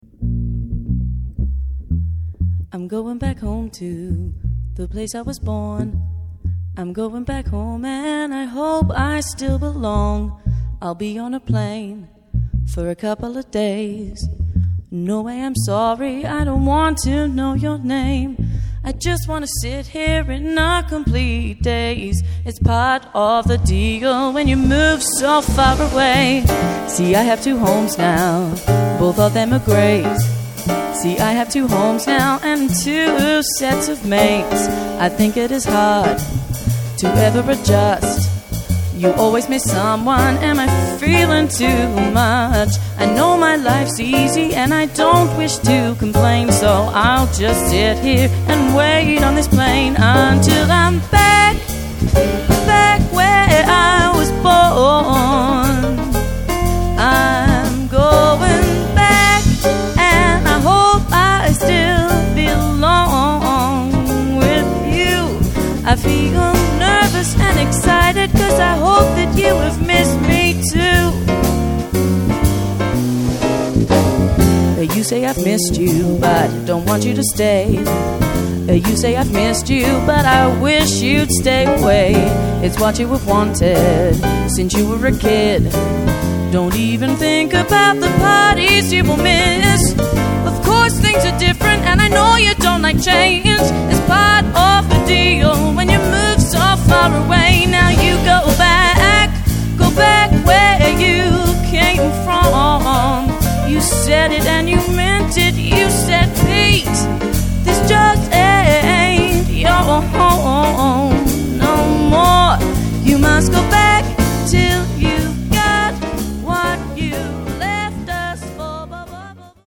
Du jazz vocal de haut vol avec une voix généreuse.
Détail combo: chant, piano, basse et batterie.